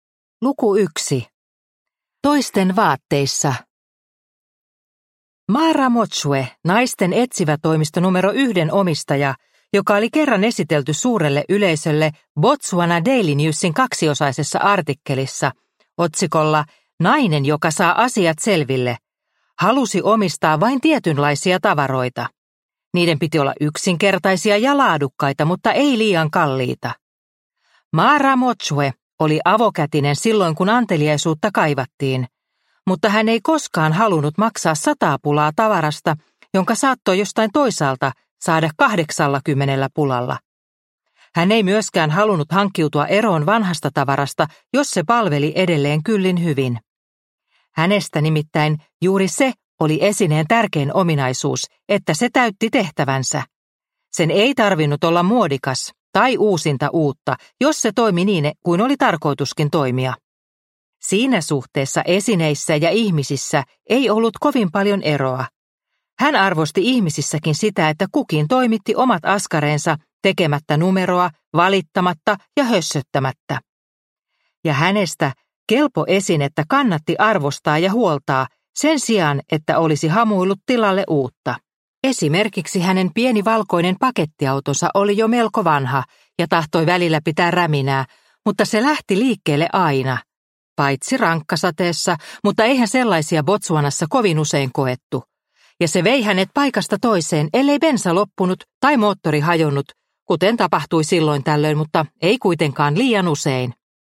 Olemmeko sukua? – Ljudbok – Laddas ner